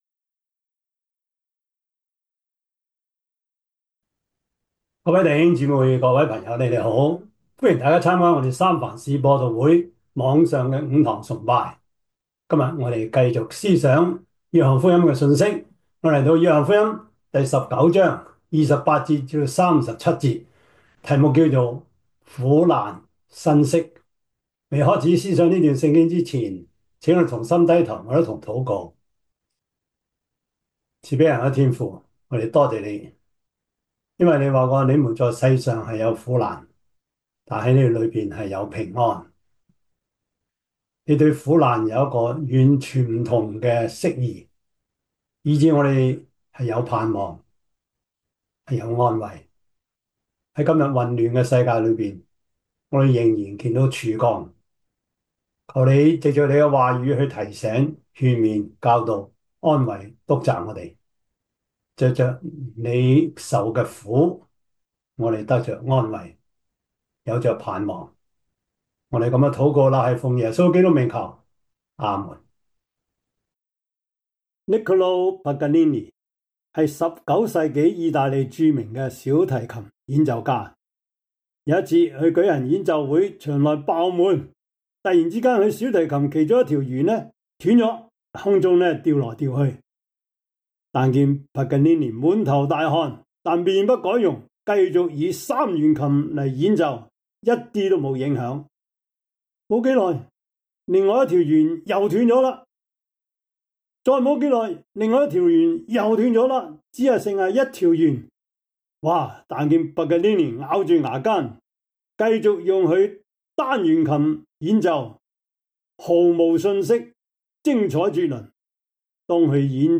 約翰福音 19:28-37 Service Type: 主日崇拜 約翰福音 19:28-37 Chinese Union Version